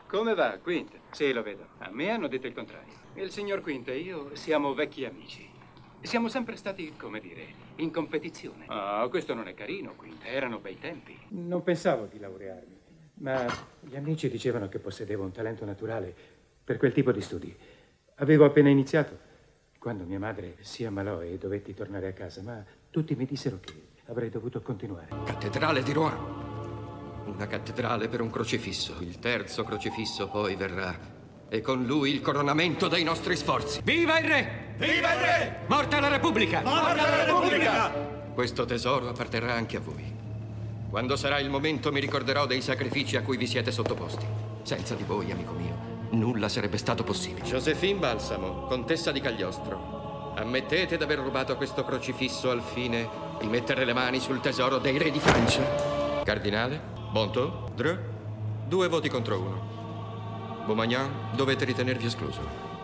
in cui doppia Lee Ving
in cui doppia Liam Neeson
FILM CINEMA